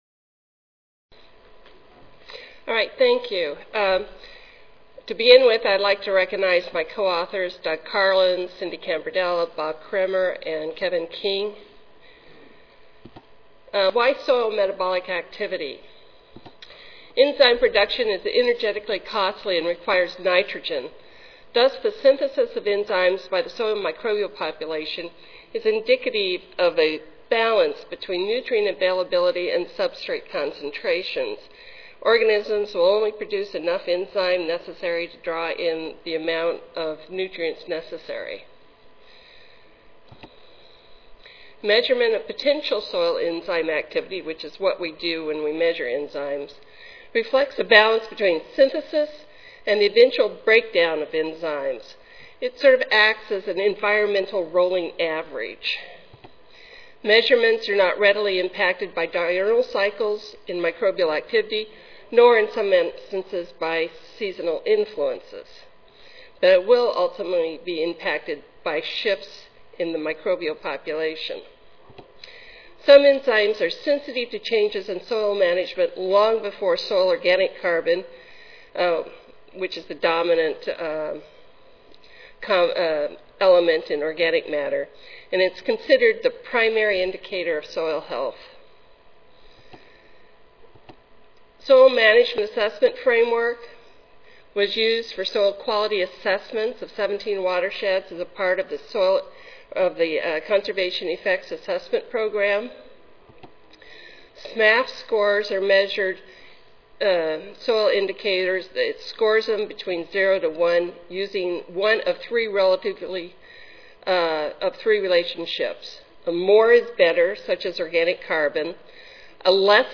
SSSA Division: Soil & Water Management & Conservation Session: Soil Quality and Conservation and Human Health (ASA, CSSA and SSSA International Annual Meetings)
Recorded Presentation